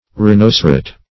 Rhinocerote \Rhi*noc"e*rote\, n.